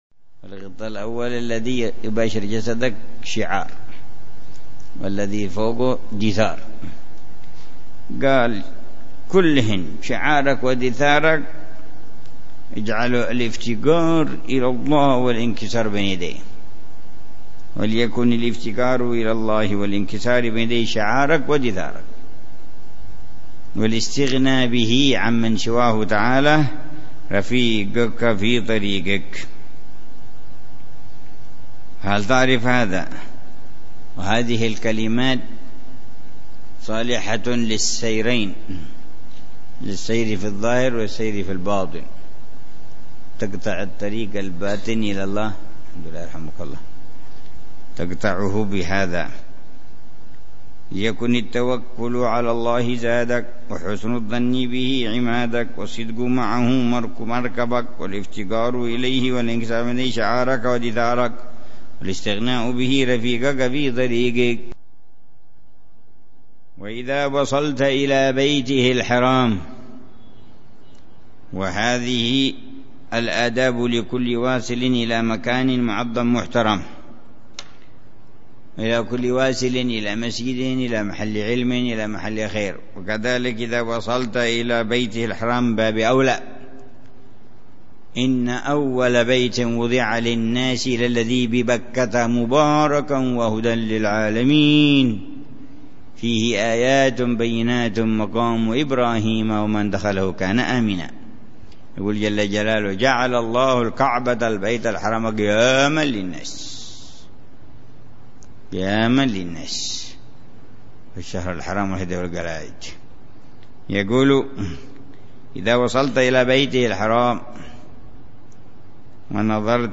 درس أسبوعي يلقيه الحبيب عمر بن حفيظ في كتاب الوصايا النافعة للإمام عبد الله بن علوي الحداد يتحدث عن مسائل مهمة في تزكية النفس وإصلاح القلب وطه